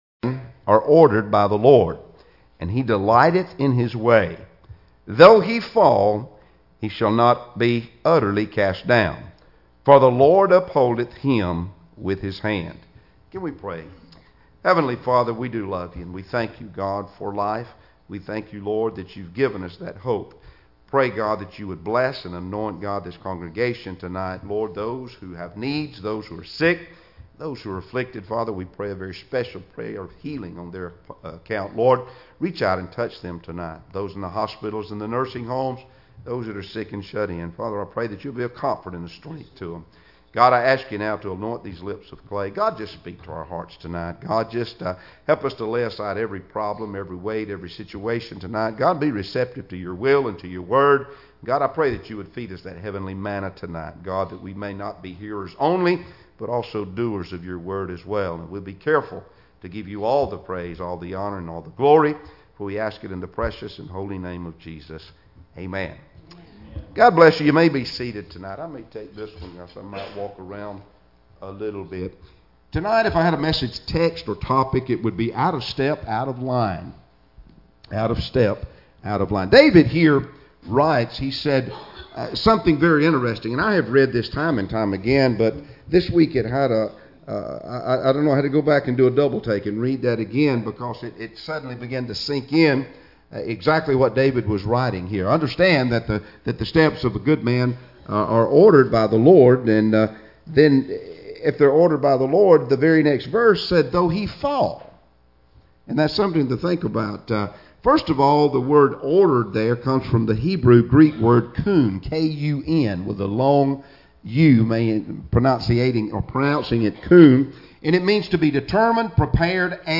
Passage: Psalms 37:23-24 Service Type: Wednesday Evening Services Topics